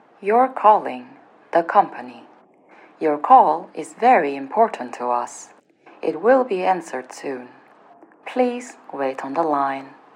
Operator_filtered1.mp3